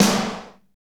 Index of /90_sSampleCDs/Northstar - Drumscapes Roland/SNR_Snares 1/SNR_F_T Snrs x